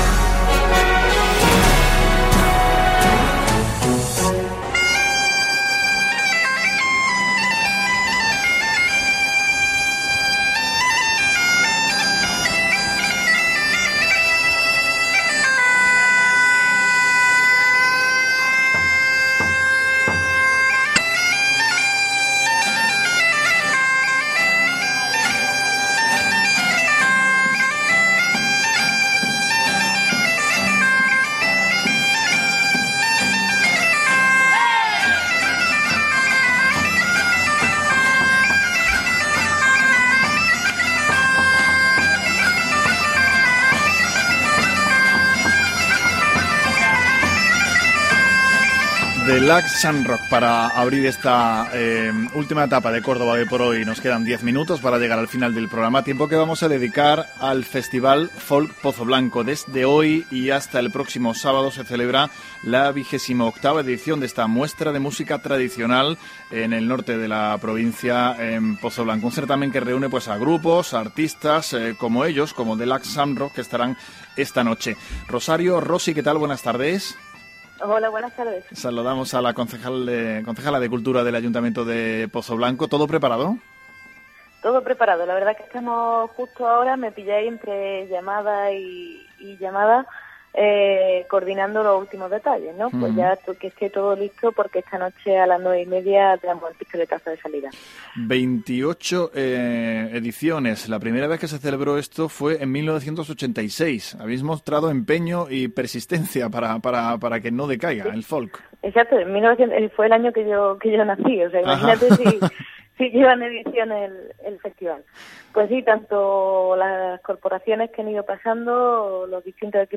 La concejala de Cultura, Rosario Rossi, ha hablado hoy en el programa “Córdoba Hoy por Hoy”, en Cadena Ser Córdoba, sobre ello: